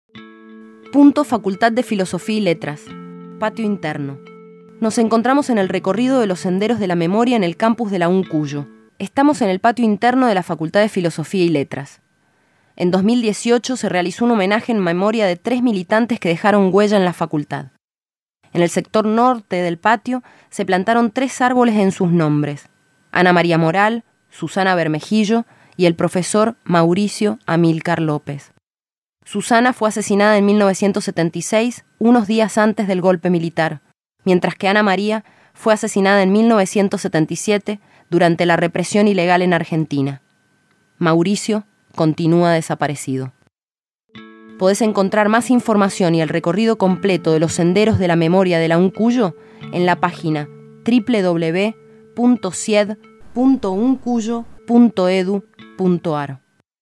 PUNTO FFYL _ PATIO INTERNO.mp3